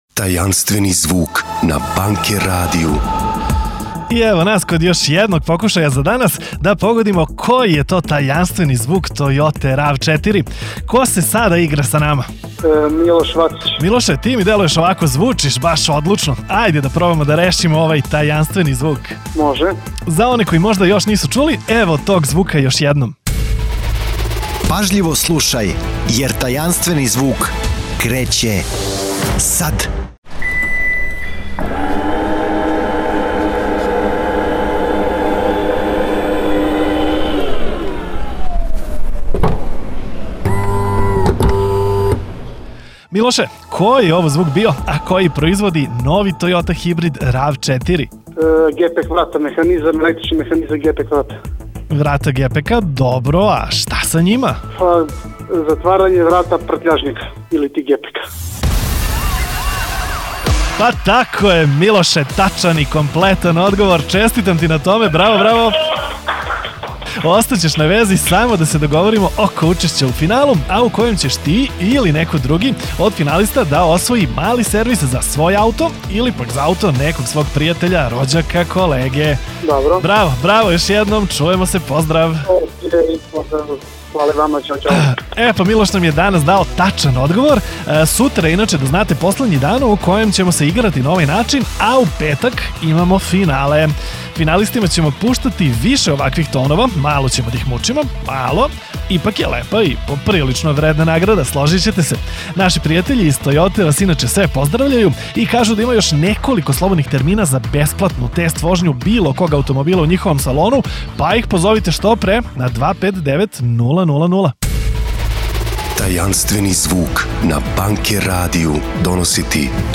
Tajanstvene zvuke koji proizvodi nova Toyota RAV4 smo snimali na licu mesta, u salonu Toyote, a onda i puštali na radiju tokom čitave nedelje, jedan dan –  jedan tajanstveni zvuk.
A u četvrtak niko nije uspeo da pogodi zvuk električnog pomeranja sedišta.